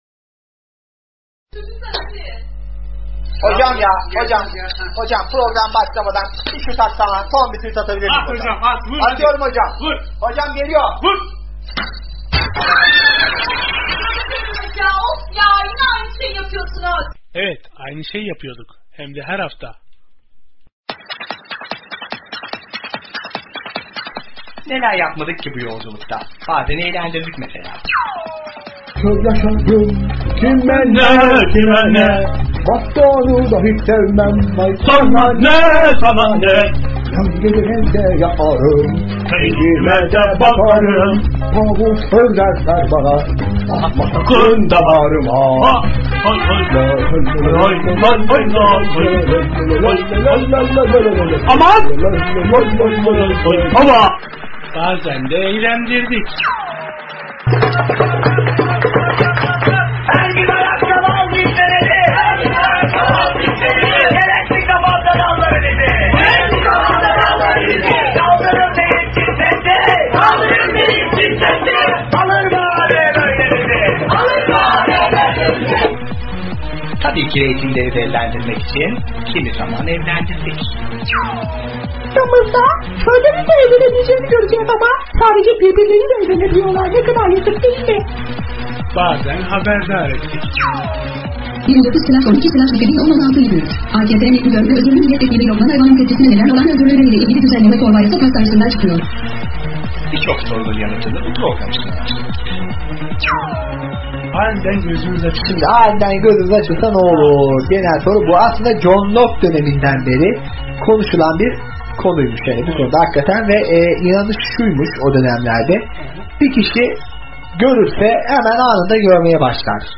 Bu hafta sesli kitap okuyan herkesin çok yakından tanıdığı bir okuyucumuzla birlikteyiz. Kendisi Altınokta kütüphanesinden seslendi bizlere. 320 civarında eser seslendirdiğini belirtiyor, ama tam sayıdan o bile emin değil.